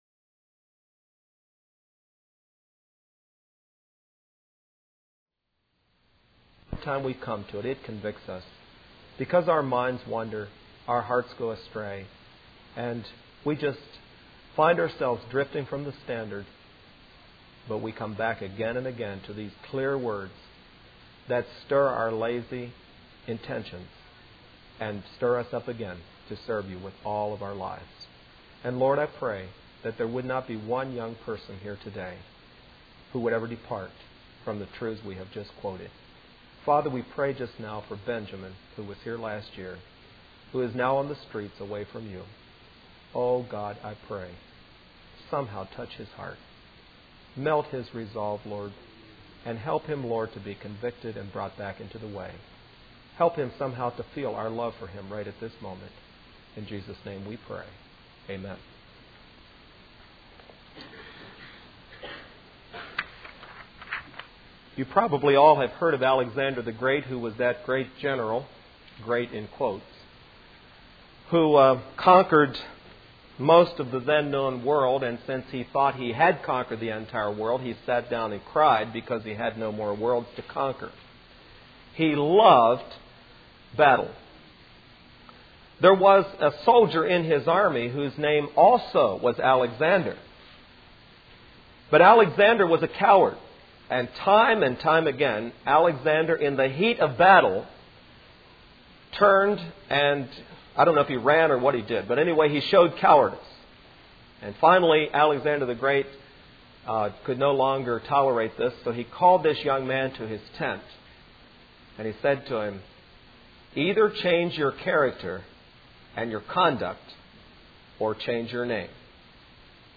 Facing The Facts Service Type: Midweek Meeting Speaker